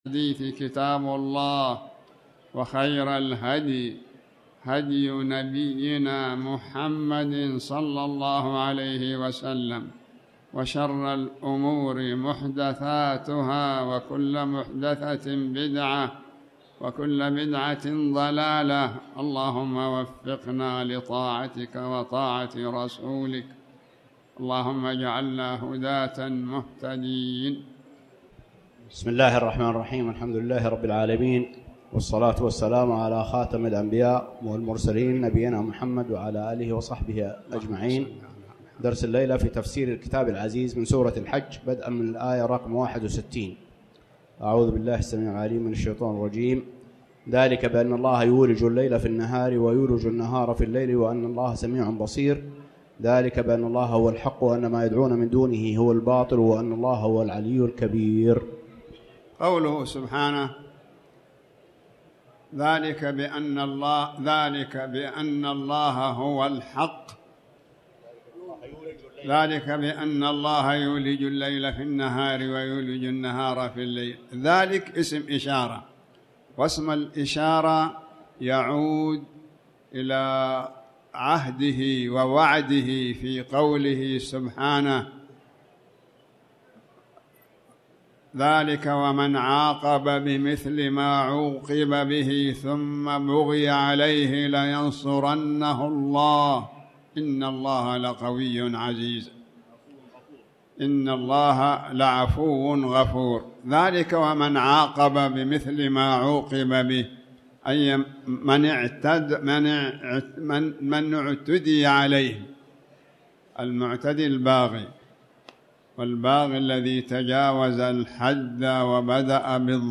تاريخ النشر ١٨ رجب ١٤٣٩ هـ المكان: المسجد الحرام الشيخ